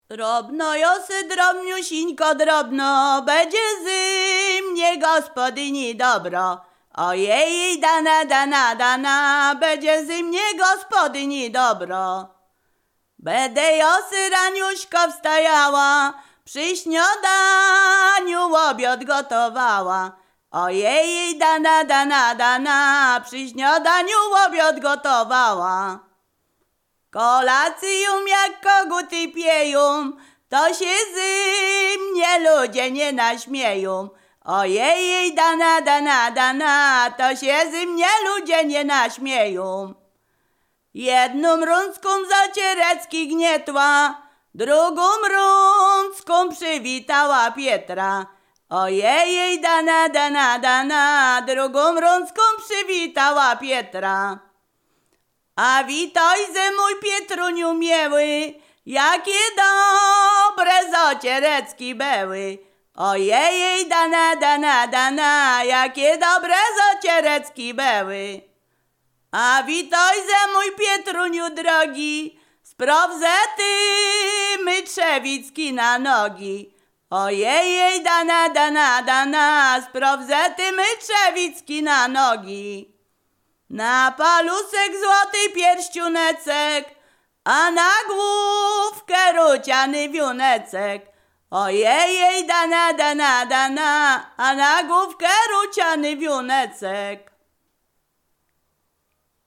Ziemia Radomska
województwo mazowieckie, powiat przysuski, gmina Rusinów, wieś Gałki Rusinowskie
liryczne miłosne weselne